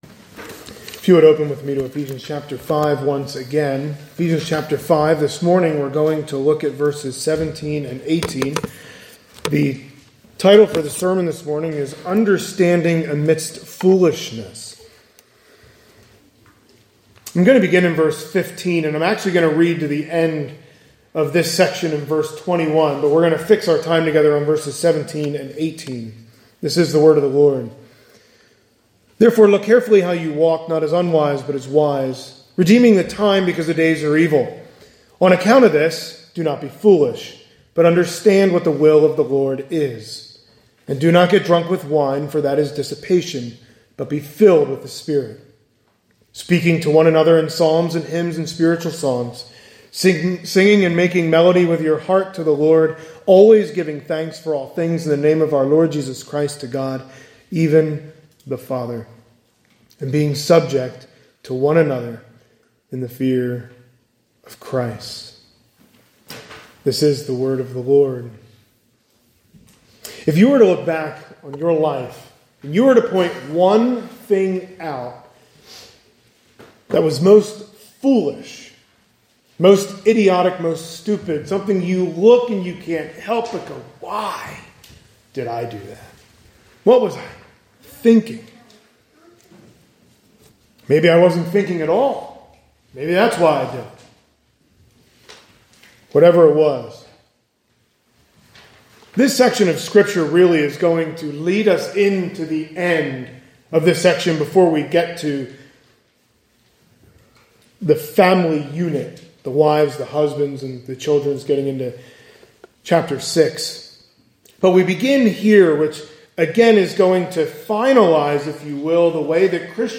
Sunday Morning Sermons | Zionsville Bible Fellowship Church